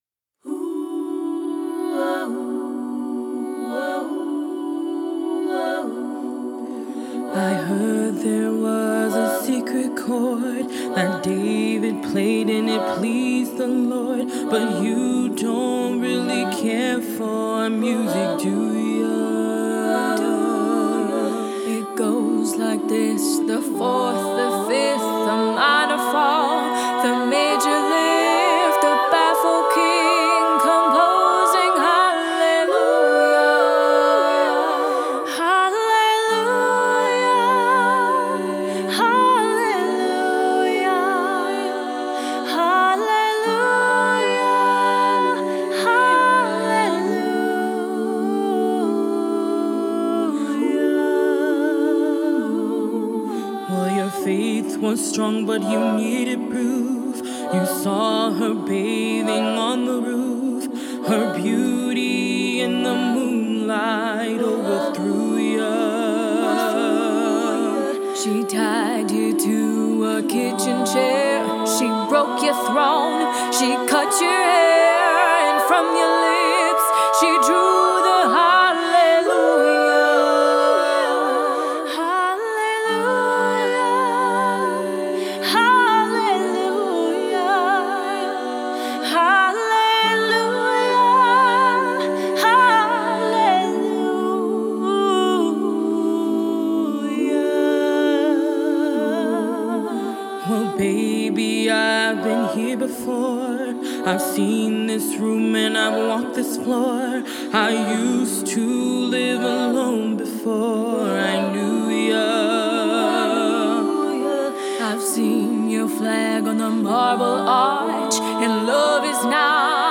Genre: Pop, Classical